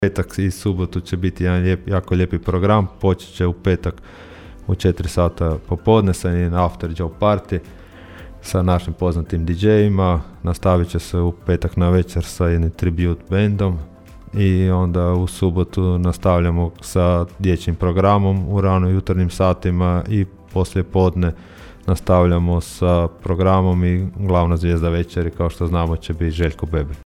ton – Donald Blašković 1), najavio je labinski gradonačelnik Donald Blašković.